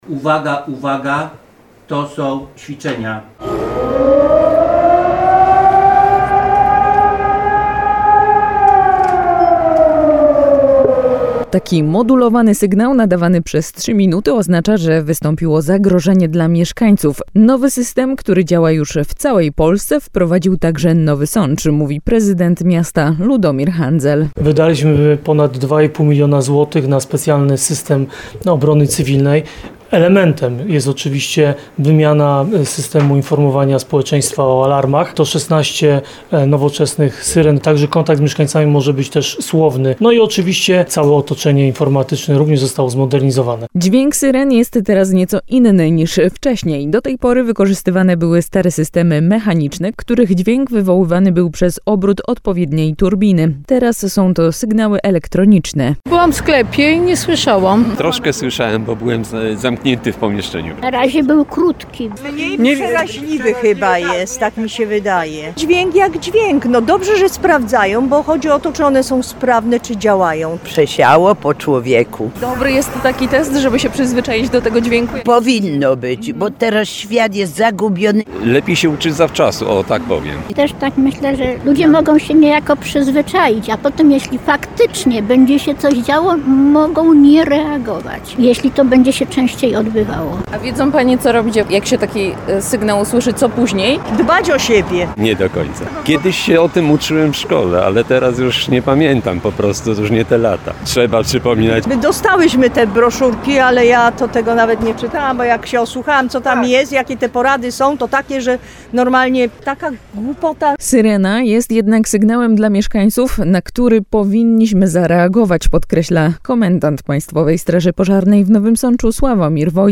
Dźwięk syren jest teraz nieco inny niż wcześniej.
Teraz są to sygnały elektroniczne.
13puls_syreny.mp3